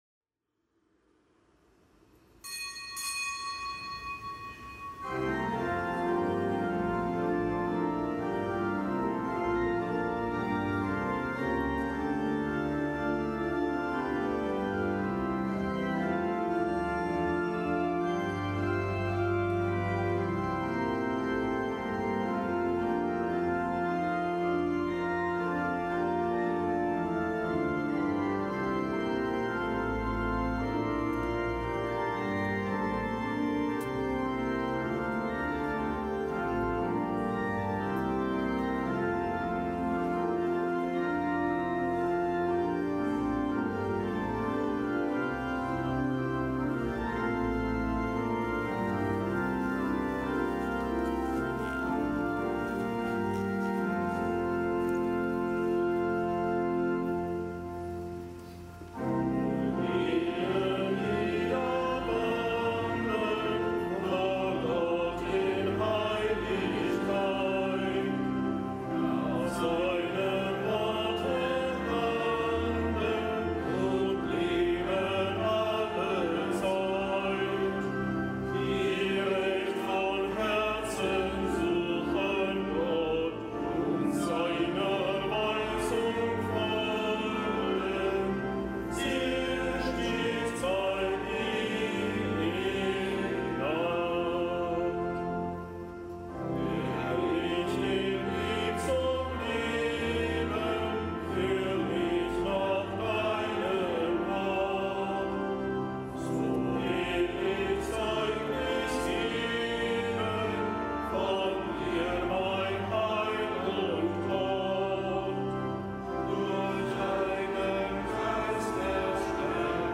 Kapitelsmesse am Fest des Heiligen Benedikt von Nursia
Kapitelsmesse aus dem Kölner Dom am Fest des Heiligen Benedikt von Nursia, Vater des abendländischen Mönchtums, Schutzpatron Europas.